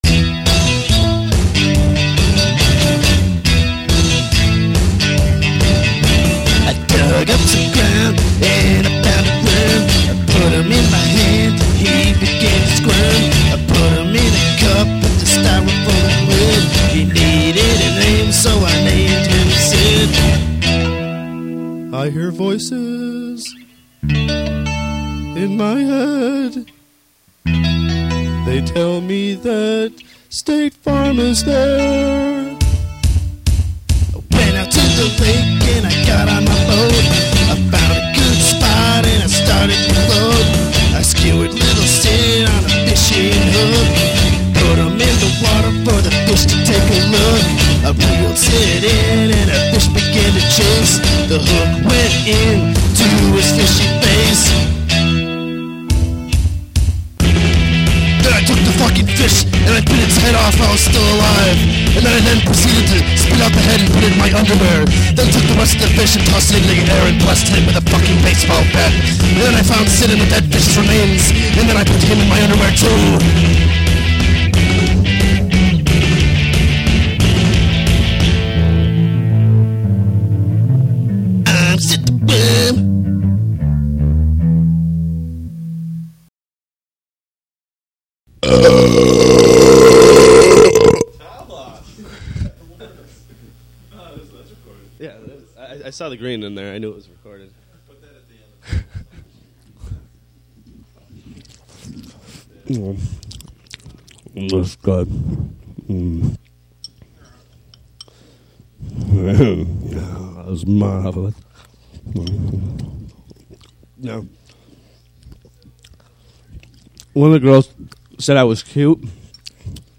All Guitar done by ?
Drums